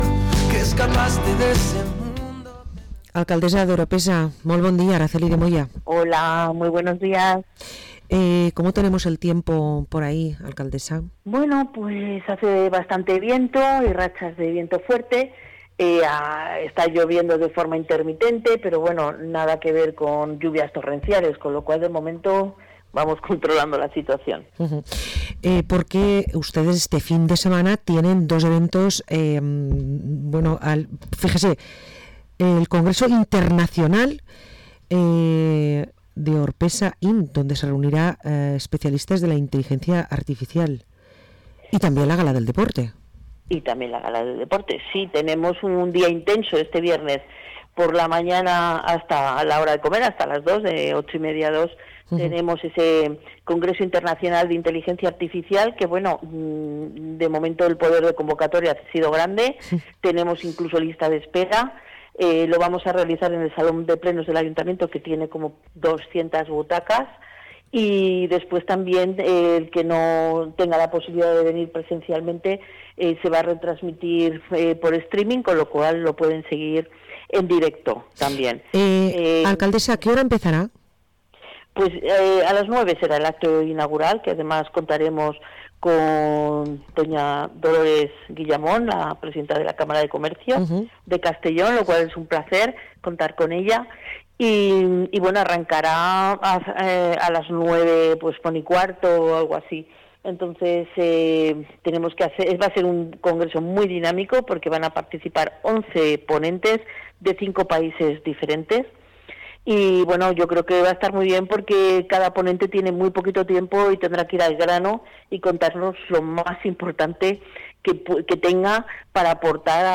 Parlem amb l´Alcaldessa d´Orpesa, Araceli de Moya